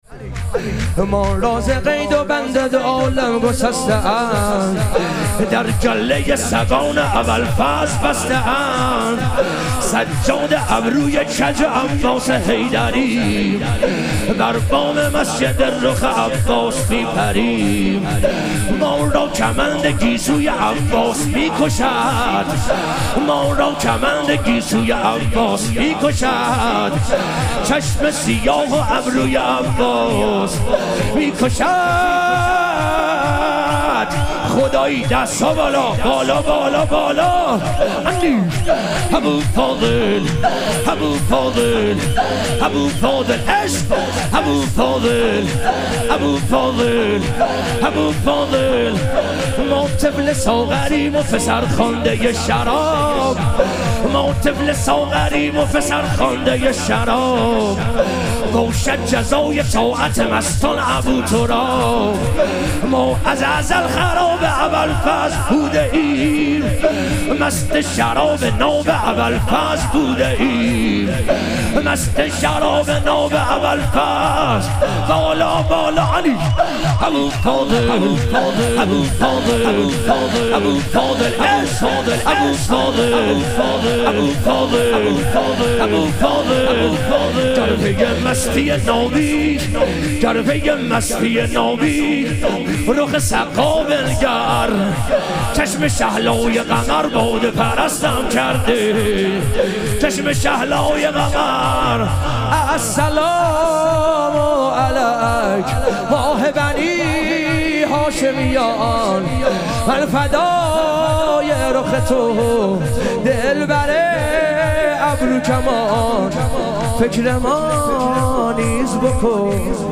شور
شب ظهوروجود مقدس امام هادی علیه السلام